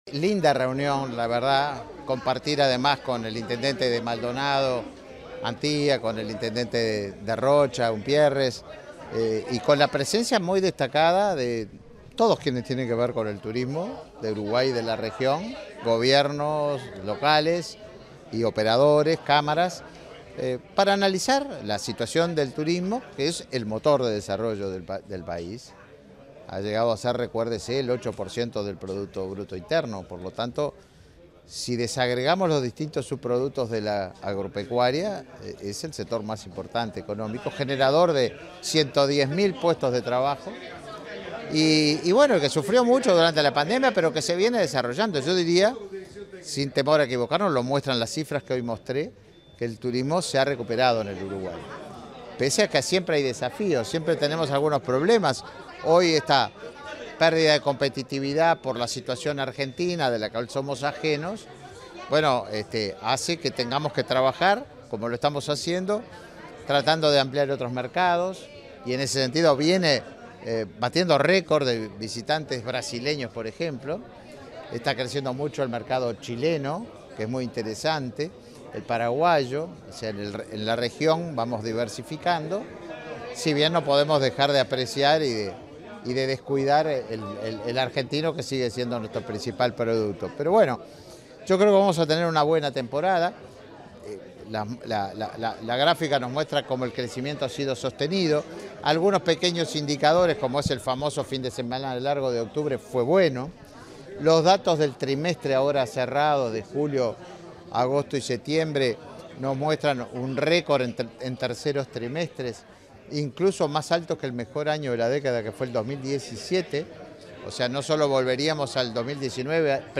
Declaraciones del ministro de Turismo, Tabaré Viera
Declaraciones del ministro de Turismo, Tabaré Viera 30/10/2023 Compartir Facebook X Copiar enlace WhatsApp LinkedIn El ministro de Turismo, Tabaré Viera, dialogó con la prensa, luego de participar de un desayuno de trabajo de la revista Somos Uruguay, realizado el pasado jueves 26, en el aeropuerto de Punta del Este.